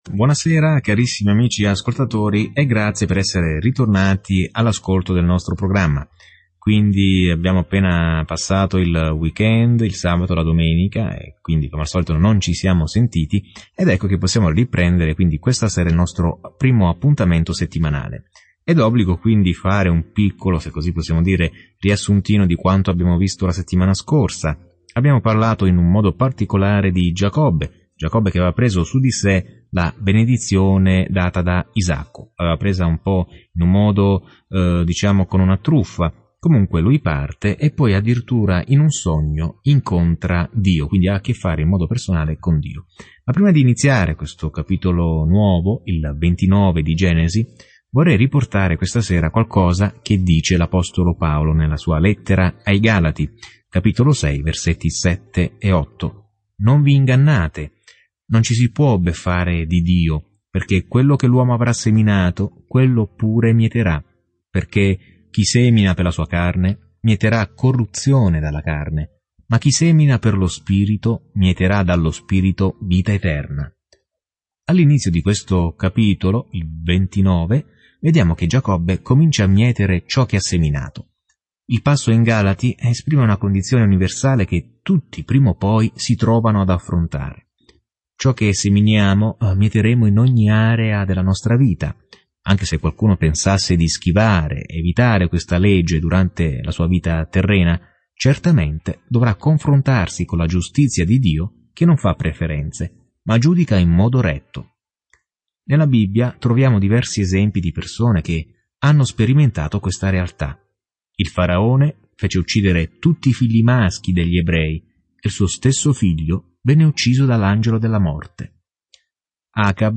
Scrittura Genesi 29 Giorno 28 Inizia questo Piano Giorno 30 Riguardo questo Piano È qui che tutto comincia: l’universo, il sole e la luna, le persone, le relazioni, il peccato, tutto. Viaggia ogni giorno attraverso la Genesi mentre ascolti lo studio audio e leggi versetti selezionati dalla parola di Dio nel libro della Genesi.